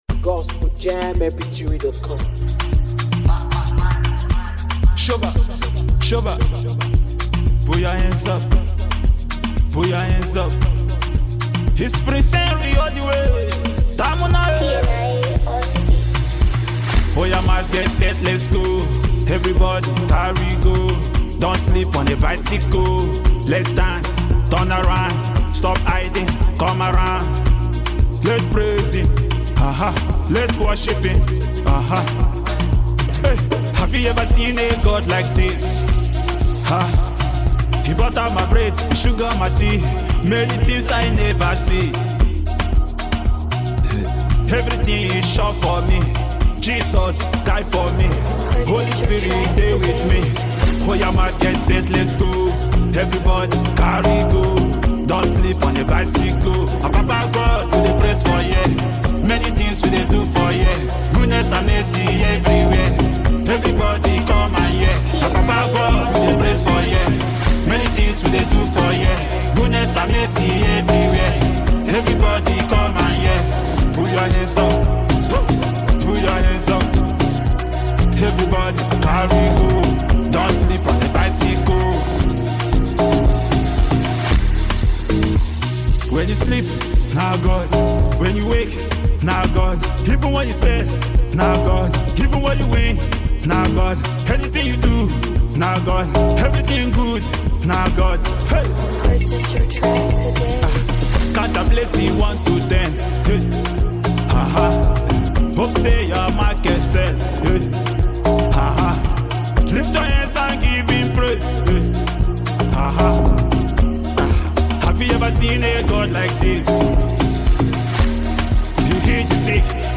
is a powerful song of triumph and thanksgiving